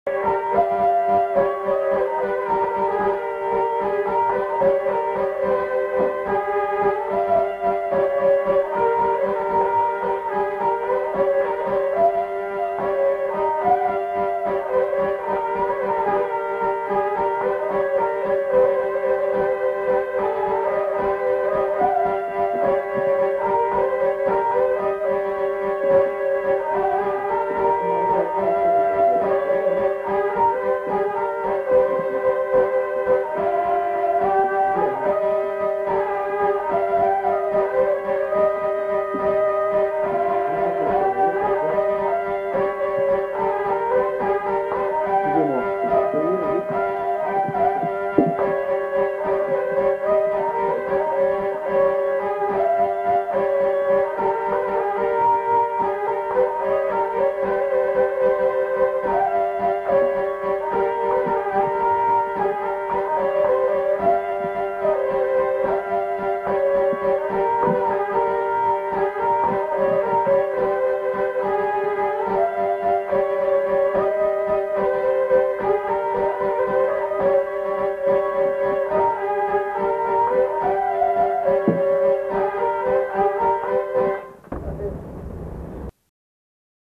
Aire culturelle : Bas-Armagnac
Lieu : Mauléon-d'Armagnac
Genre : morceau instrumental
Instrument de musique : vielle à roue
Danse : valse